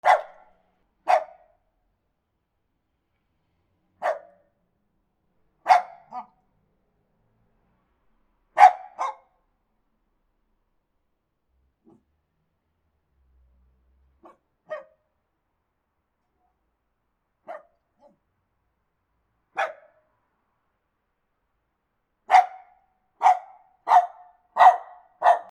犬の鳴き声
/ D｜動物 / D-15 ｜犬